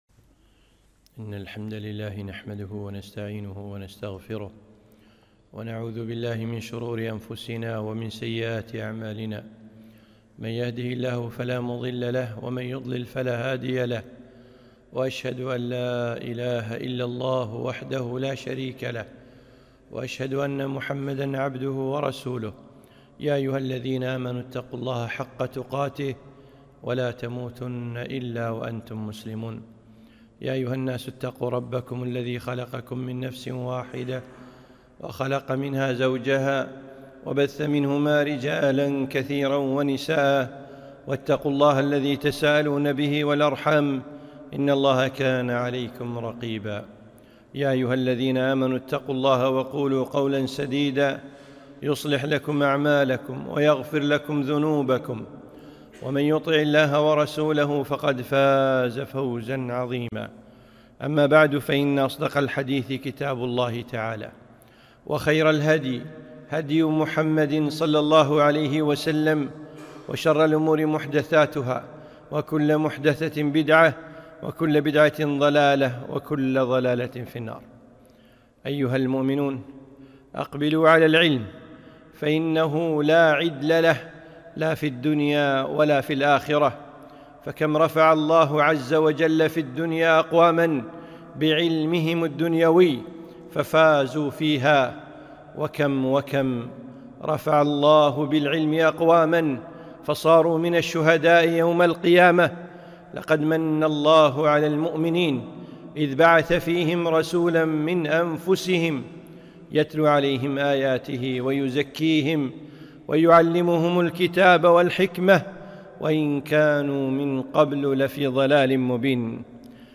خطبة - اقبلوا على العلم